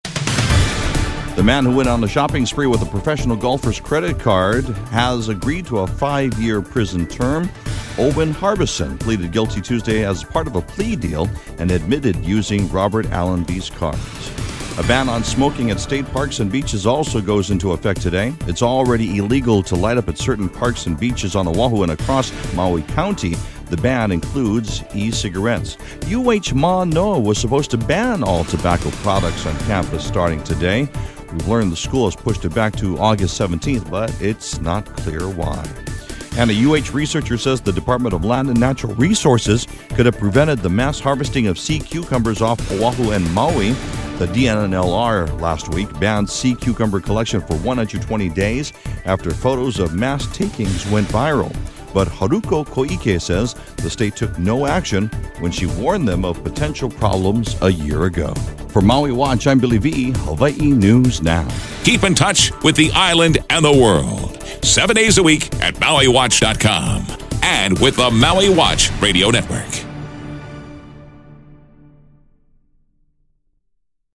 Your daily news & weather brief for July 1, 2015